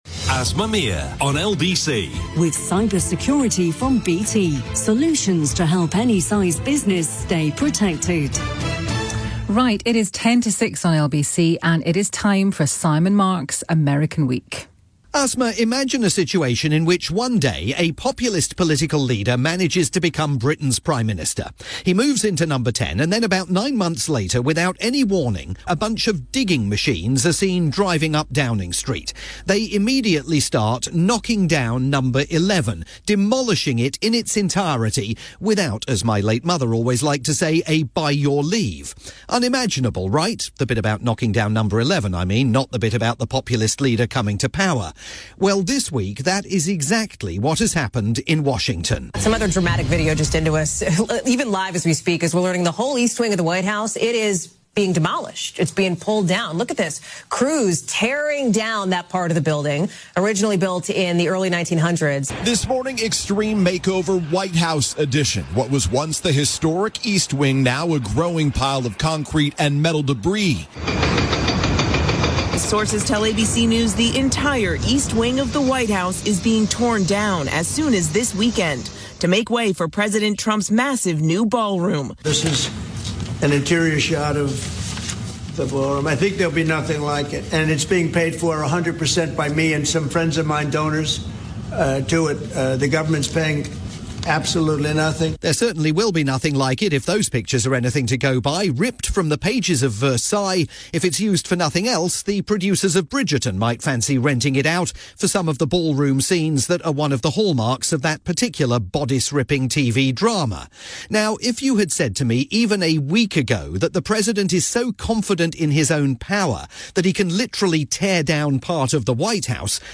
Listen live every Friday at 5:50pm on LBC, or find it here on-demand afterwards.